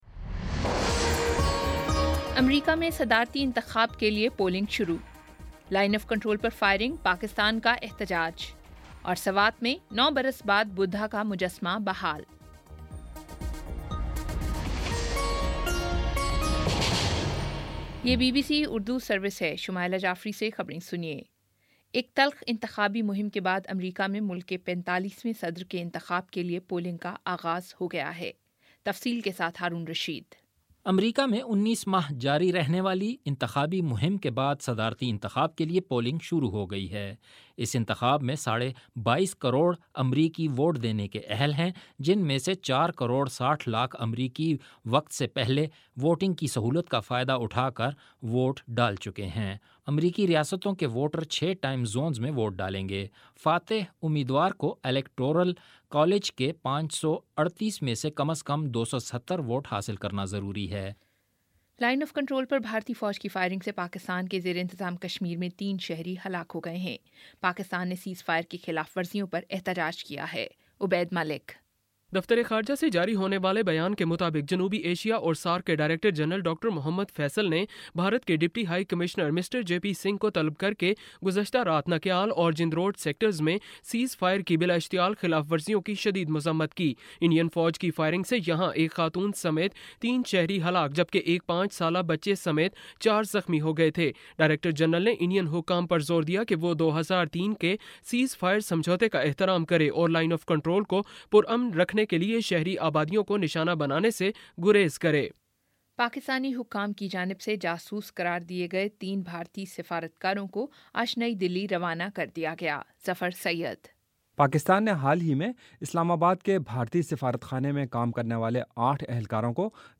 نومبر 08 : شام سات بجے کا نیوز بُلیٹن